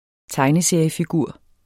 Udtale [ ˈtɑjnəˌseɐ̯ˀjəfiˈguɐ̯ˀ ]